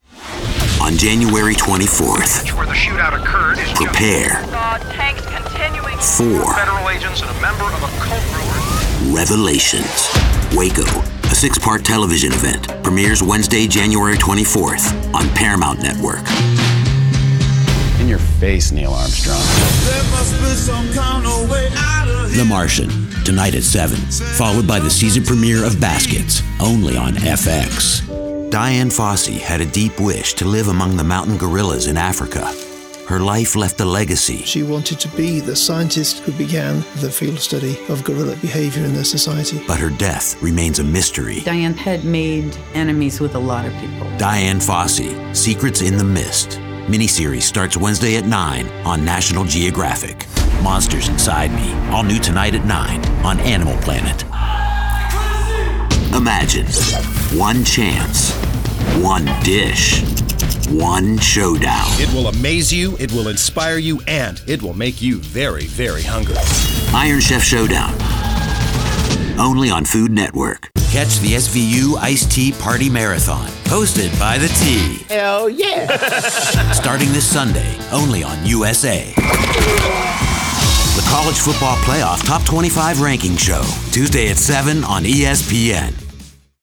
Penetrating, Urgent, Unique.
TV Promo